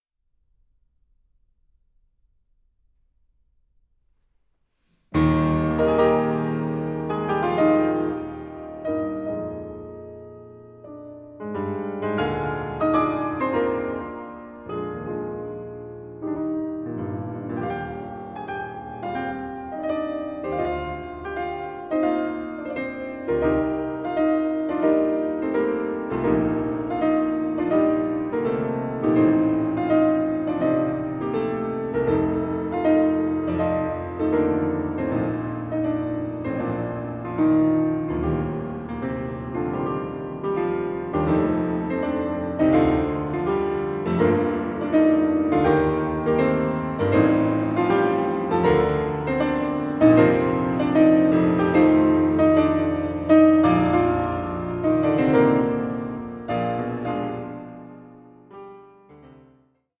Prelude in E-flat major